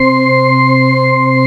Index of /90_sSampleCDs/AKAI S-Series CD-ROM Sound Library VOL-8/SET#5 ORGAN
ORGAN 1.wav